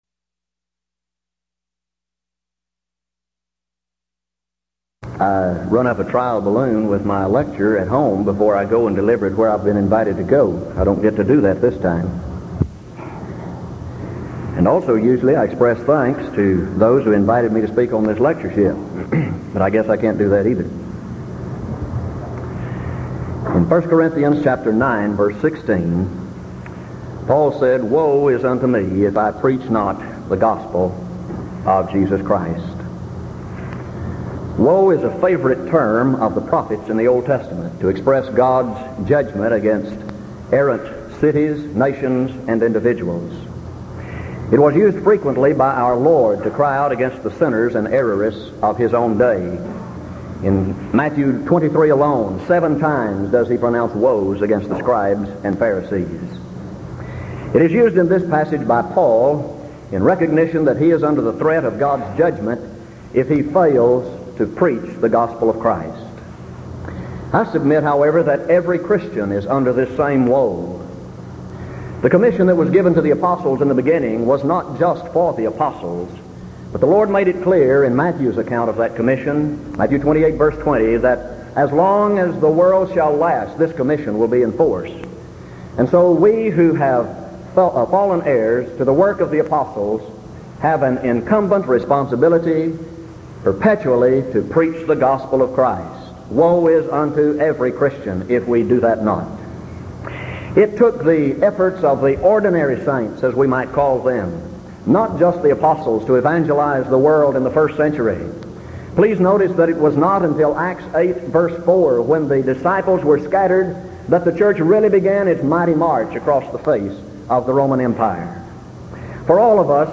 Event: 1982 Denton Lectures Theme/Title: Studies in 1 Corinthians
lecture